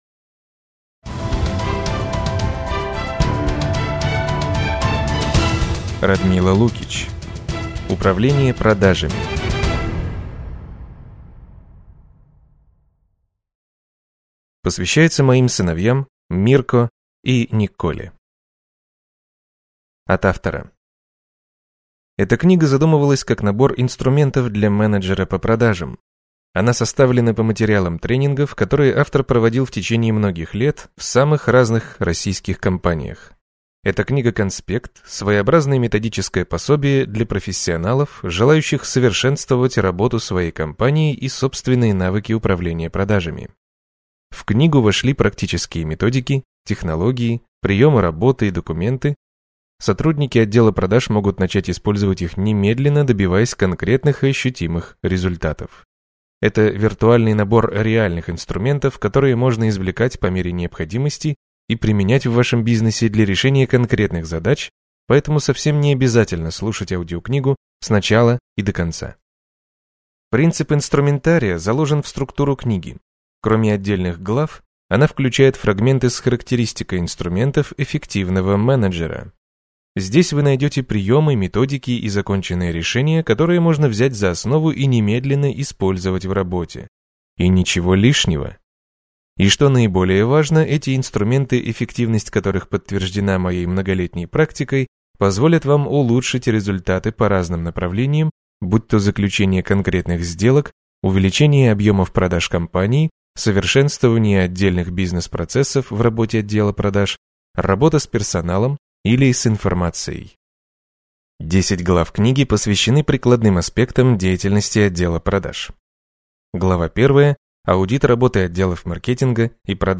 Аудиокнига Управление продажами | Библиотека аудиокниг